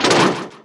start_rolling.wav